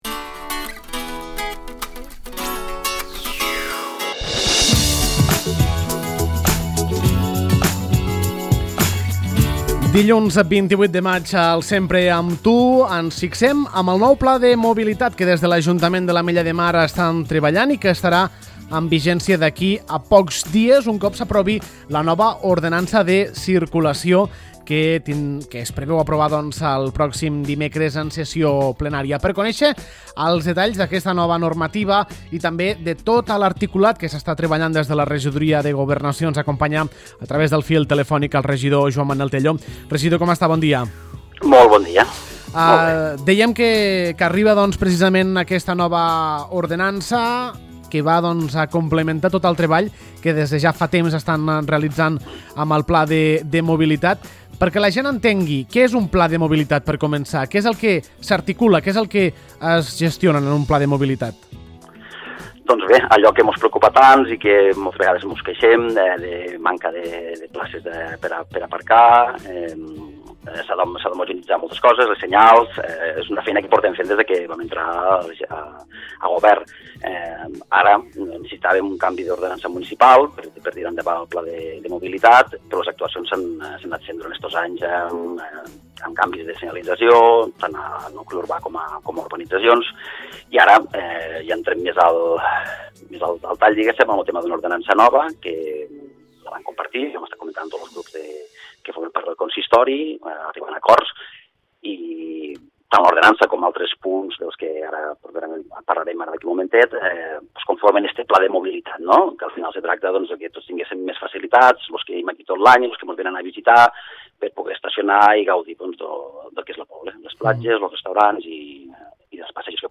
El regidor de Governació, Joan Manel Tello, explica el nou pla de mobilitat de l’Ametlla de Mar, que s’actualitza gràcies a una nova ordenança de circulació.